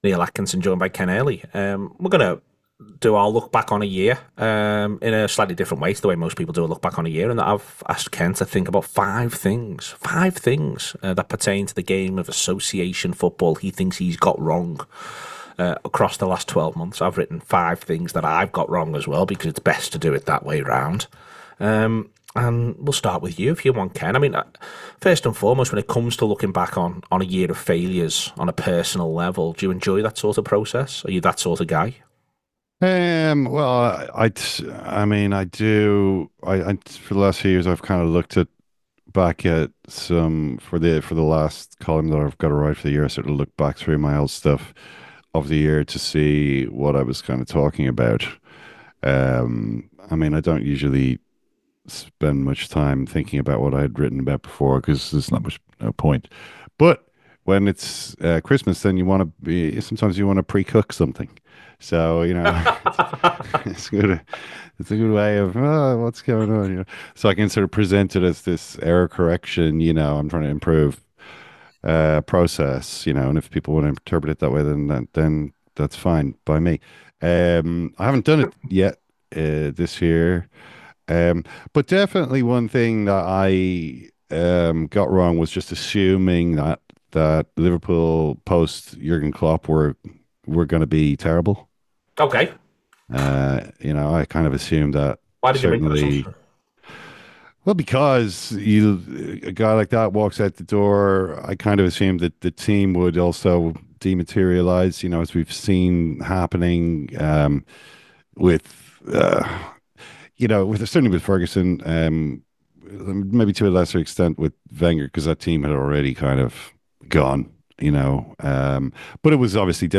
Below is a clip from the show – subscribe for more on Liverpool post-Jürgen Klopp…